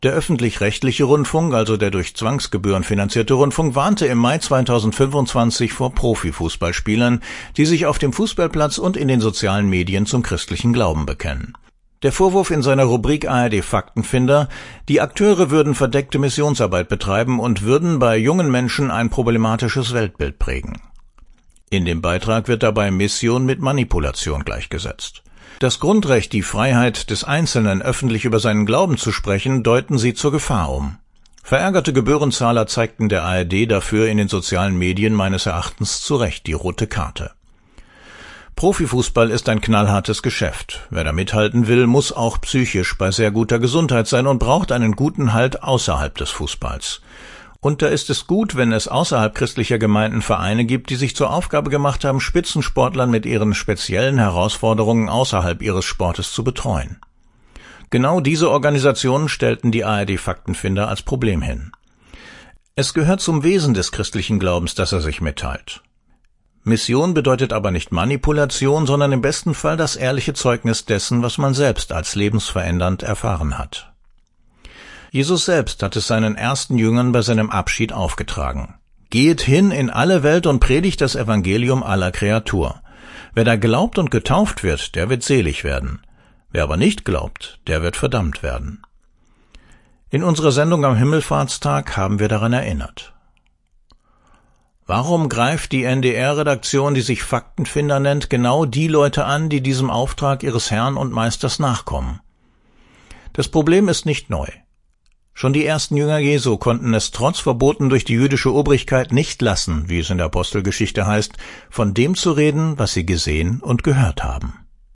Kommentar